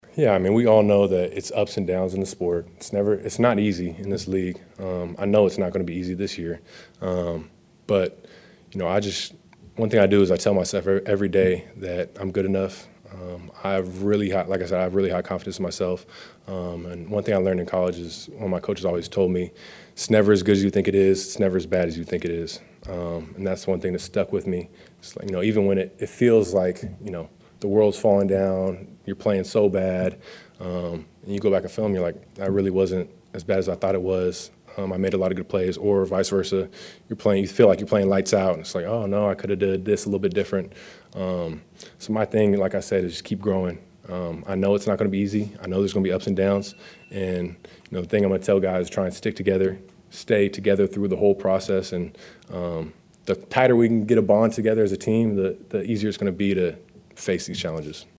Jordan Love, QB1, meets the press at Lambeau.